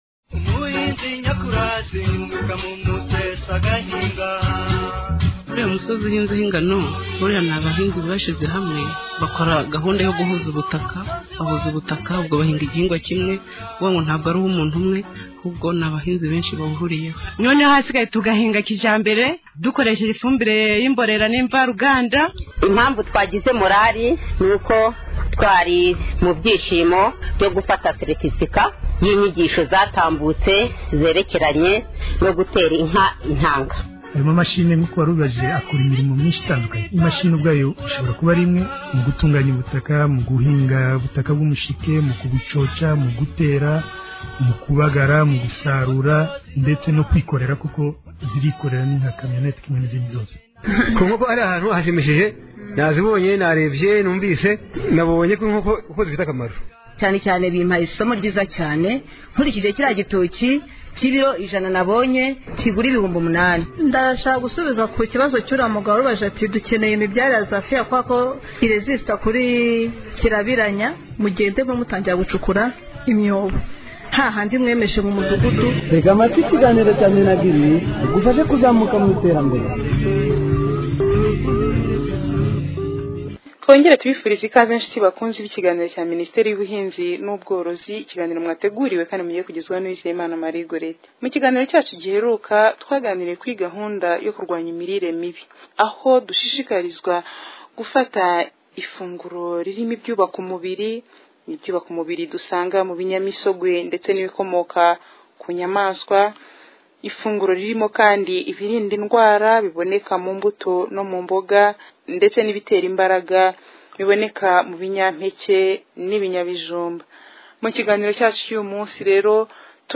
01. Ikiganiro: Gahunda yo kuhira ku buso buto